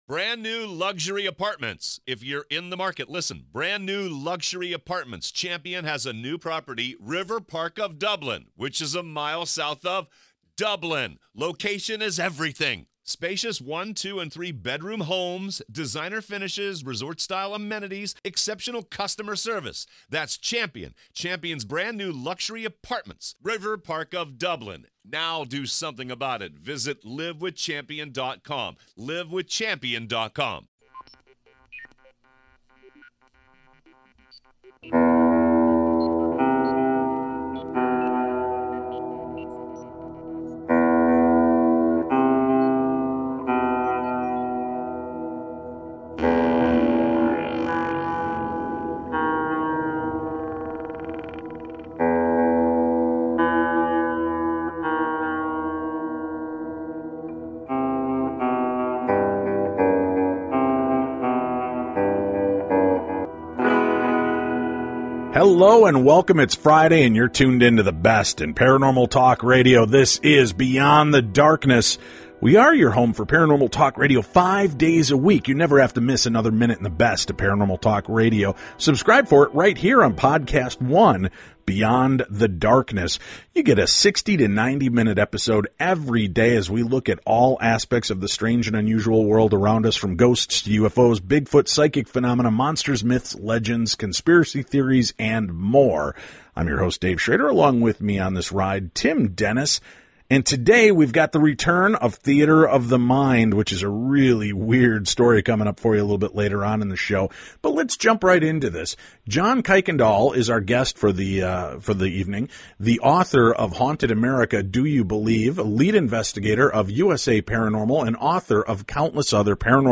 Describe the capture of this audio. PLUS A CHILLING NEW THEATER OF THE MIND!